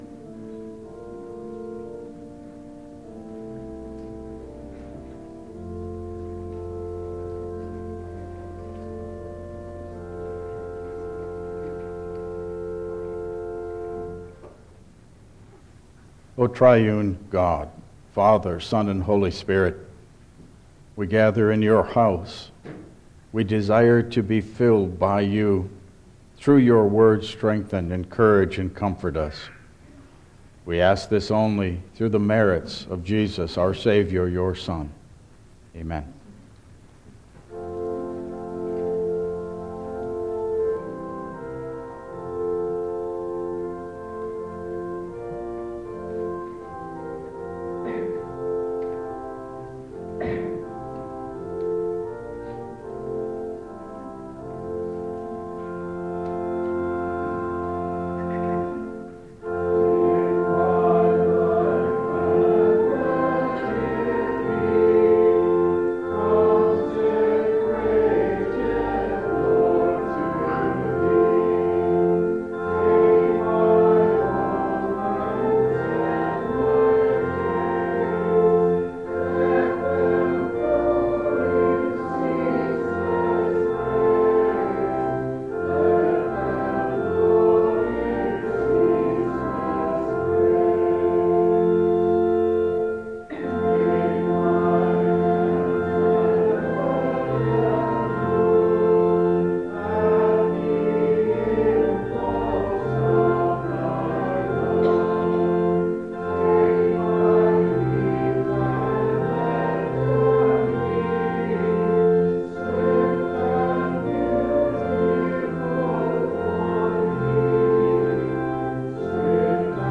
Passage: Luke 5:1-11 Service Type: Regular Service